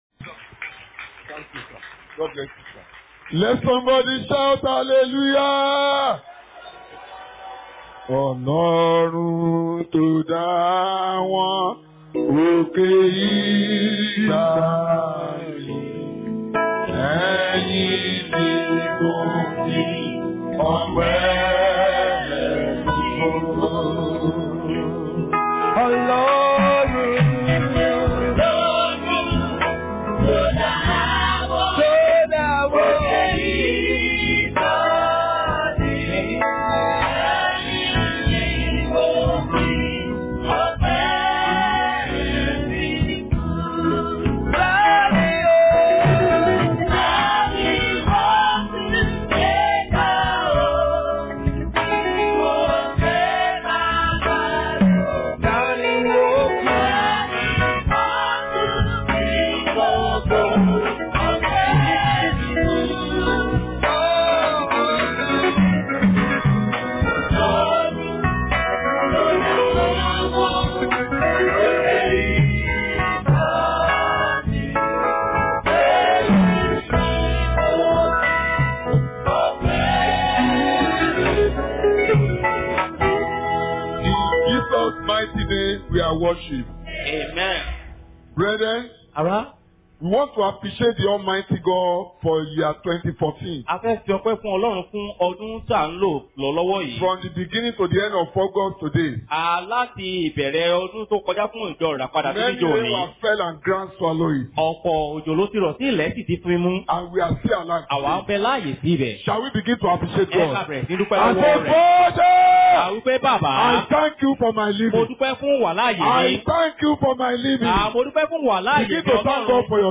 Posted in Breakthrough Service Tagged with Breakthrough , Overflowing